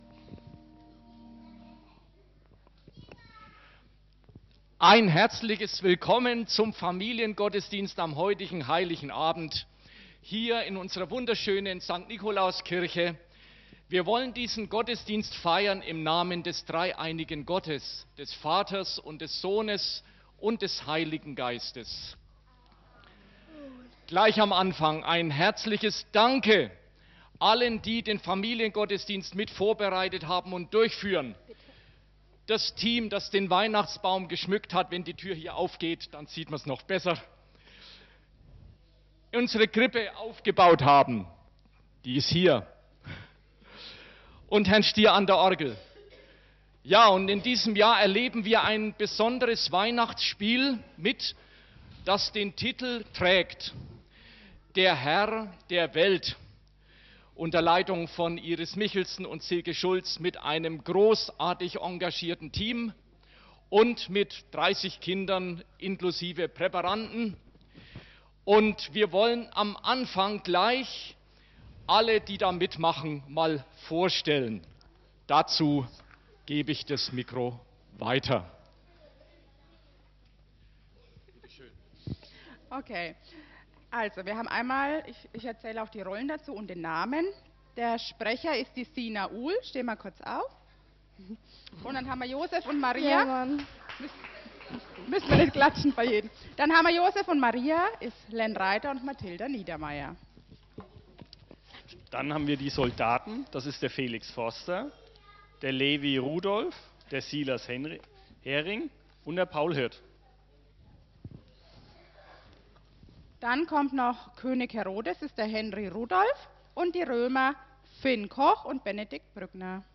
241224_16_FamGoDi_Der.Herr.der.Welt_k.mp3